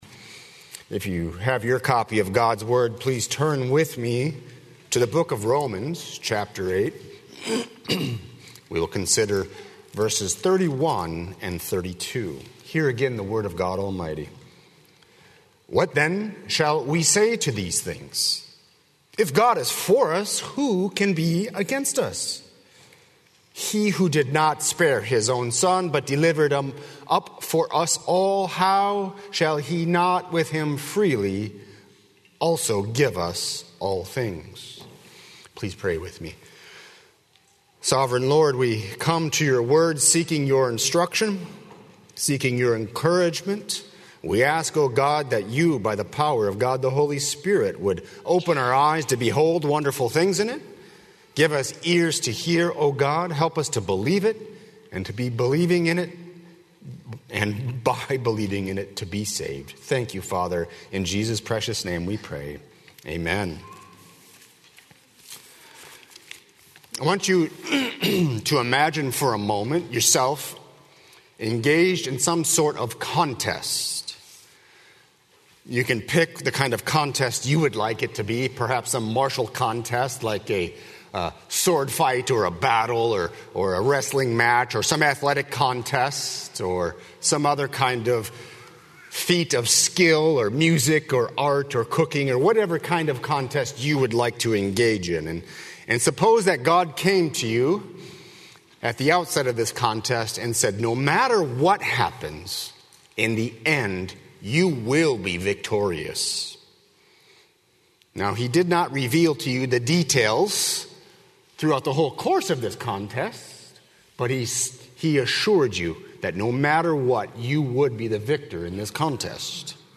00:00 Download Copy link Sermon Text Romans 8:31–32